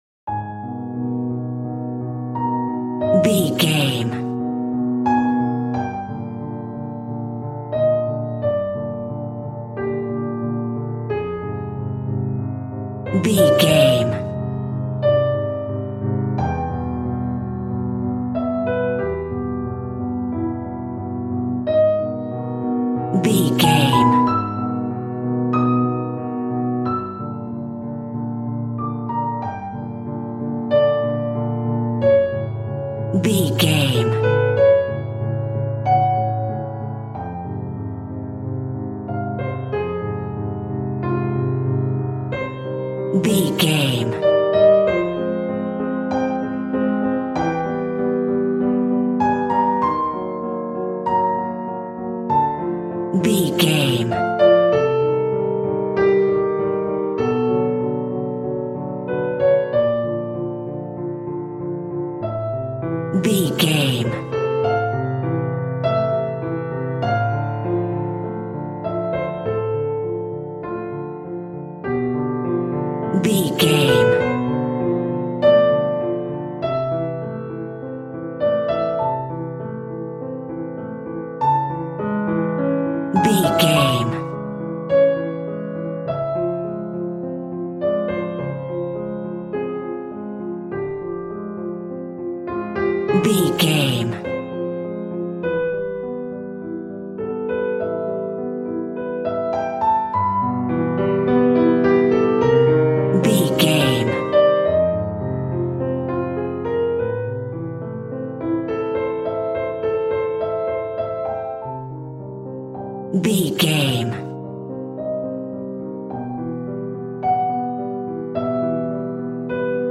A Traditional Horror Cue.
Aeolian/Minor
G#
Slow
tension
ominous
haunting
eerie